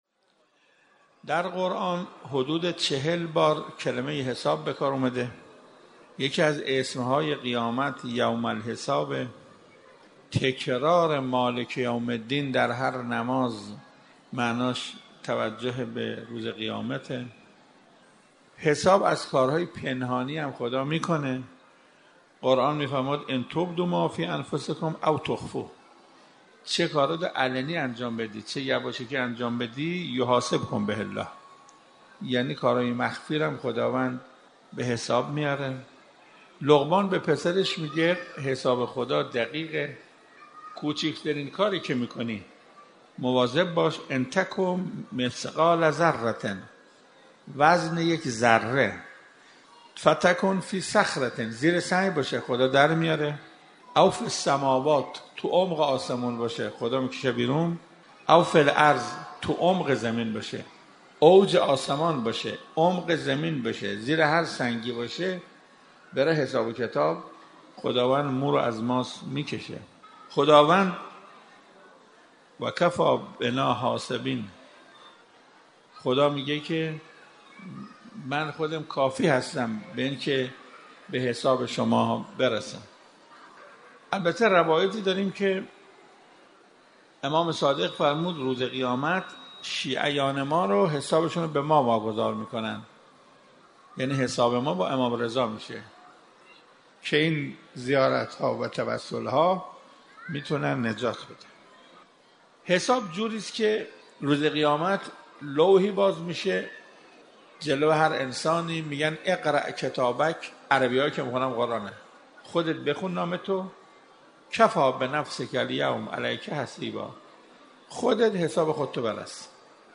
حجت‌الاسلام والمسلمین قرائتی در یکی از سخنرانی‌های خود به‌ مساله «روز حساب» اشاره می‌کند که در ادامه تقدیم مخاطبان می‌شود.